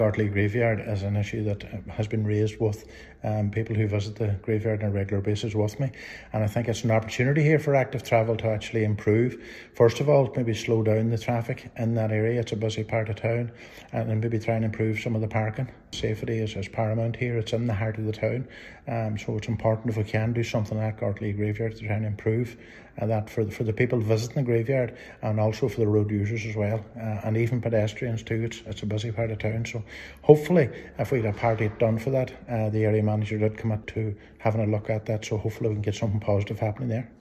Councillor Ciaran Brogan says given the number of people attending the graveyard, efforts need to be made to protect their safety: